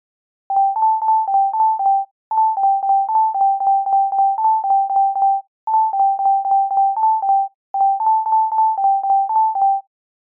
Rhinoで、sine波の一つだけのOscillatorでPitchを+12にし、Filterなし、Effectなしの状態をベースとして、
各巻ごとにOscillatorのlevel envelopeのみを変えています。
先頭に0.5秒の無音部を入れています。
巻七の音色